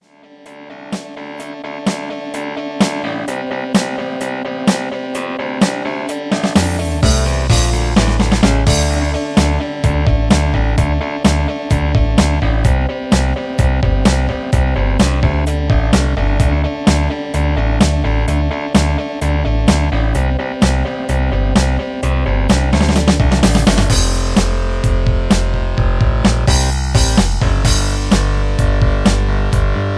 Karaoke MP3 Backing Tracks
Just Plain & Simply "GREAT MUSIC" (No Lyrics).